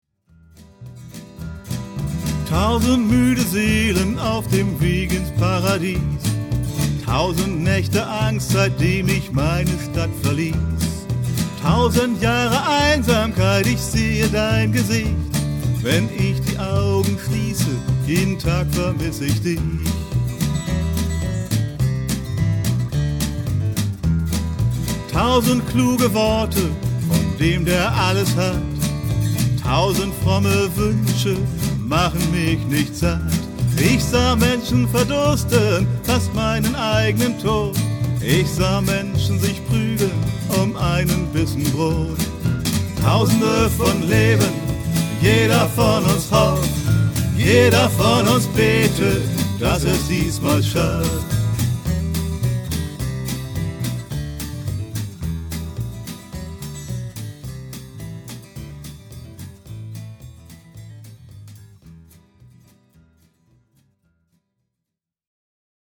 Gitarre, Gesang
Gesang, Perkussion
Bass